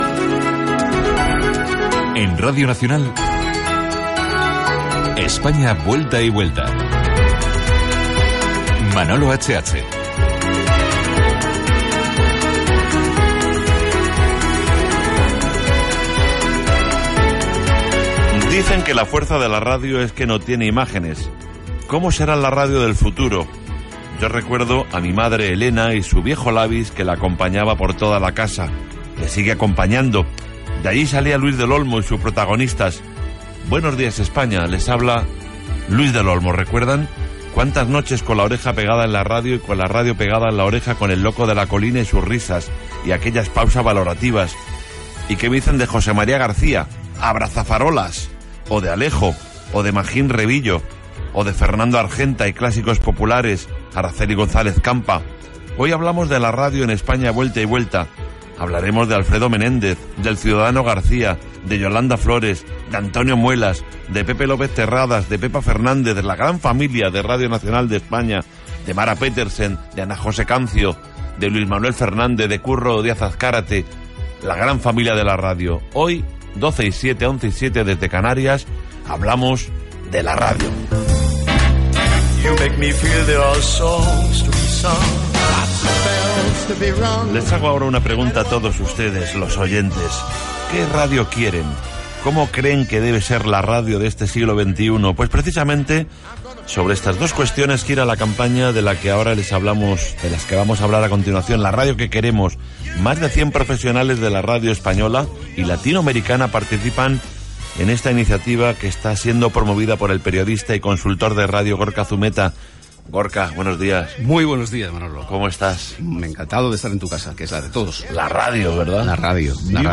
Indicatiu del programa. Espai dedicat a la ràdio.